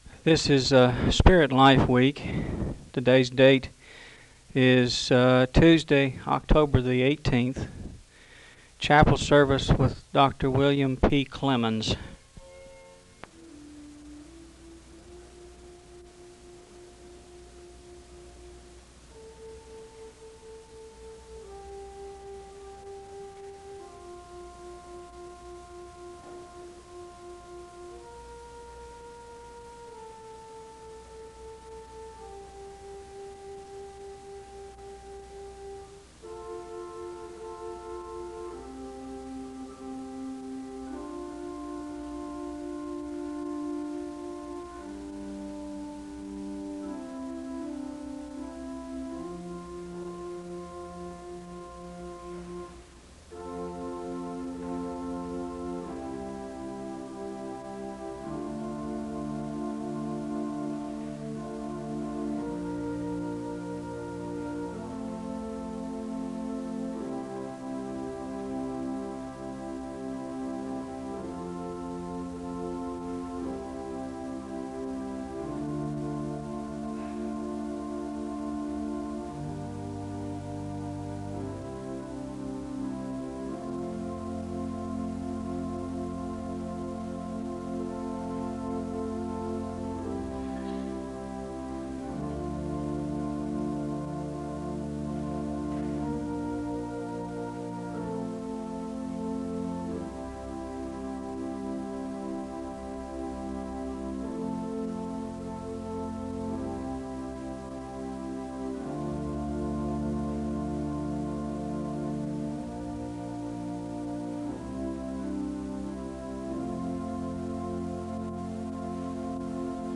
A hymn is played (0:14-3:55). The choir sings a song of worship (3:56-8:26). More singing commences after an explanation is given on how the congregation is supposed to join the choir (8:27-19:00). There is a moment of silence (19:01-1958). Scripture reading is provided from the book of Joel (19:59-21:17). Psalm 23 is sung (21:18-24:54).
The service concludes with the choir singing (39:38-42:50).